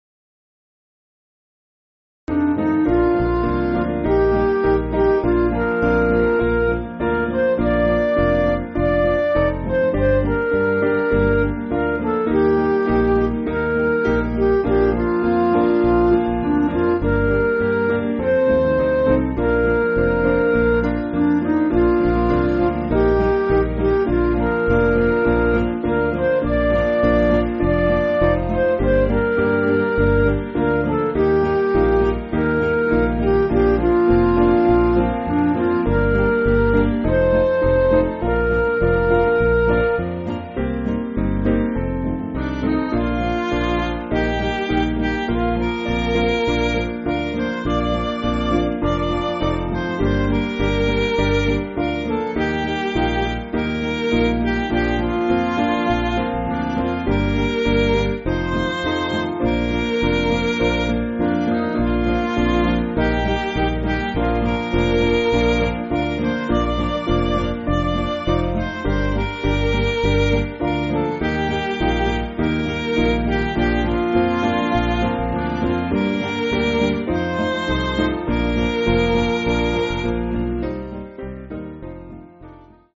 Piano & Instrumental